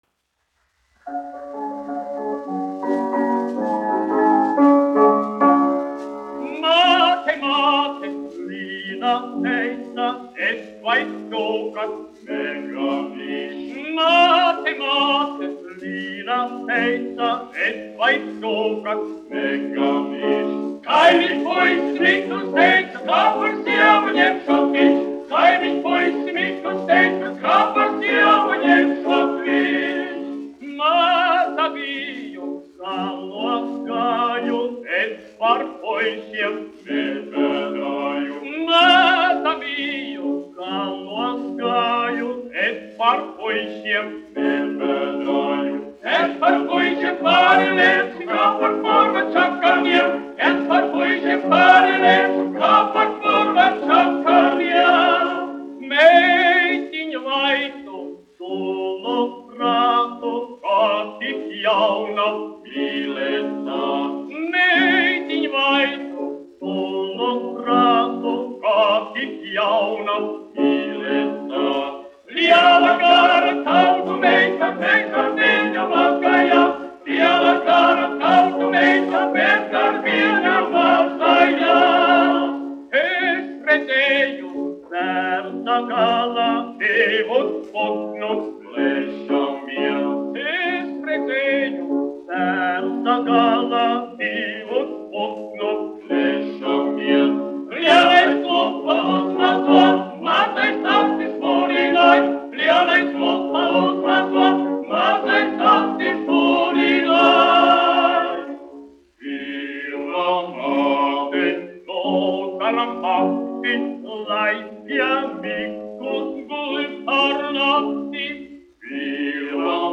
1 skpl. : analogs, 78 apgr/min, mono ; 25 cm
Populārā mūzika
Vokālie seksteti